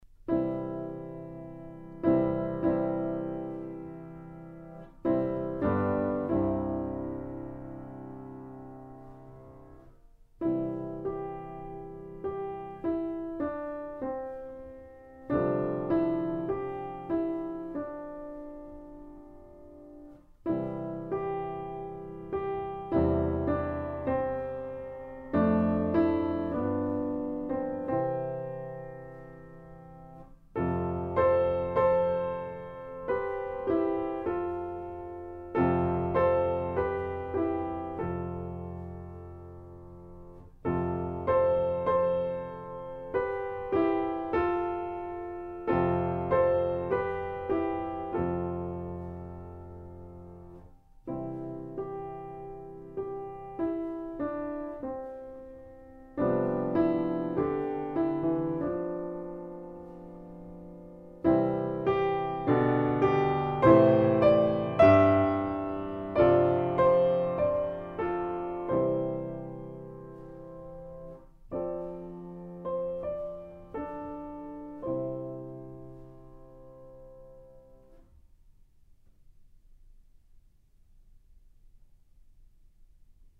Enregistrement piano
nouveau-monde-2e-mvt-piano-seul.mp3